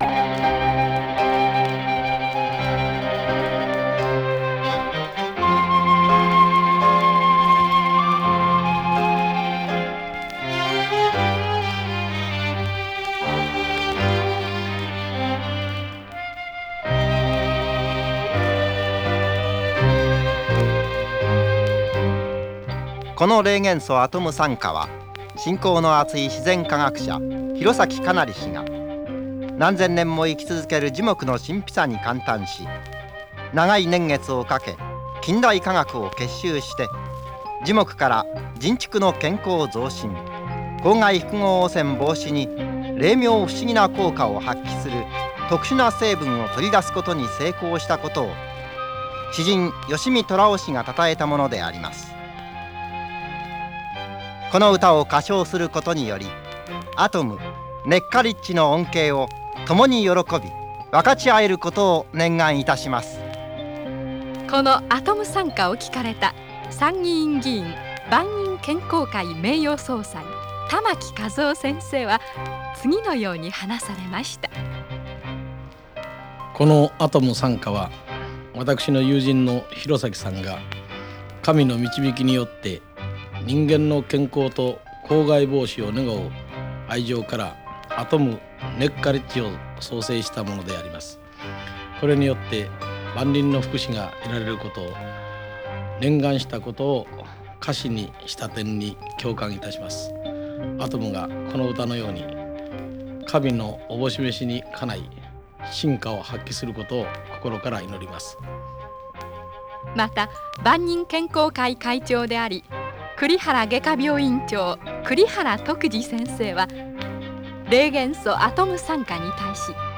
(2)解説・霊元素アトム讃歌 (22.2MB)